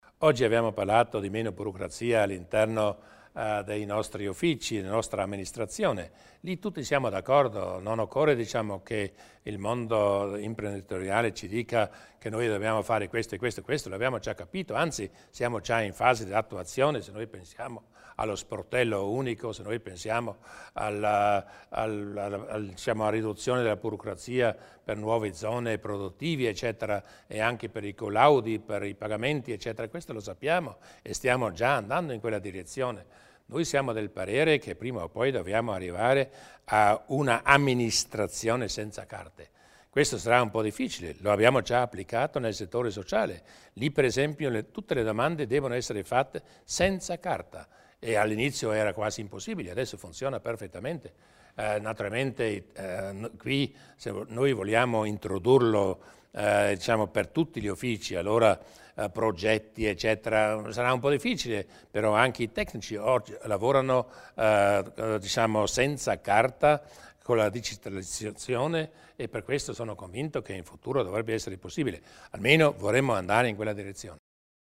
Il Presidente Durnwalder illustra le strategie per la riduzione della burocrazia